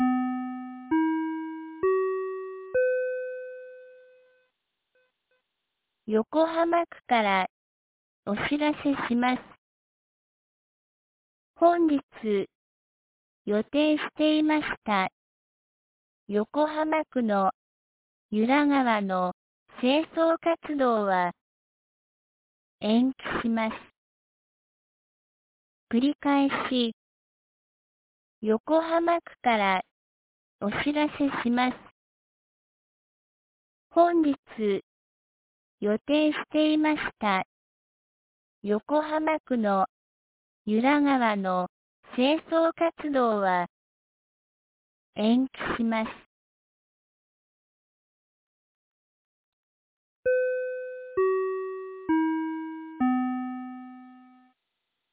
2022年11月06日 07時32分に、由良町から横浜地区へ放送がありました。